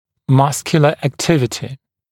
[‘mʌskjulə æk’tɪvətɪ][‘маскйулэ эк’тивэти]мышечная активность